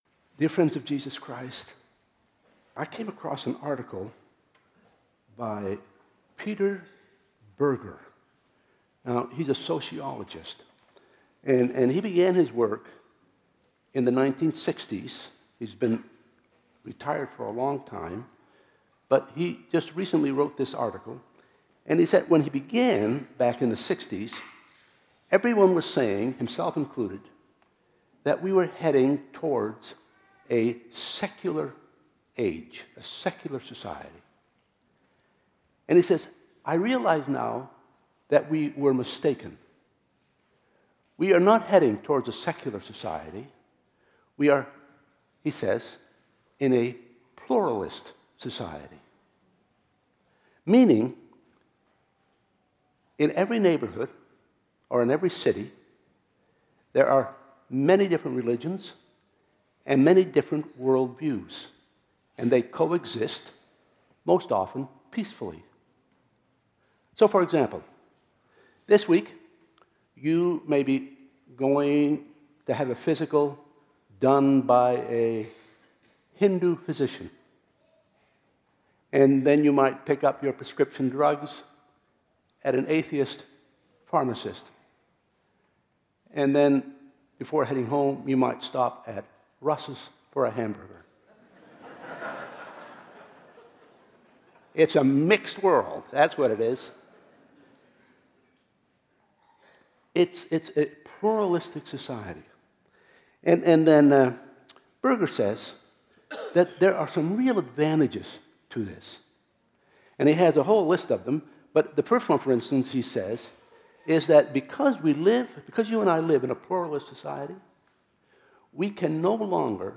2016 Sermons